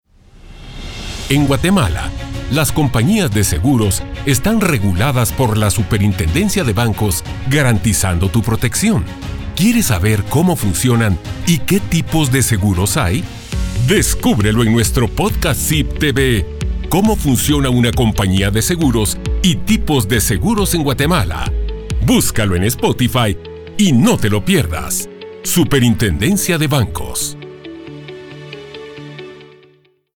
Anuncios en Radio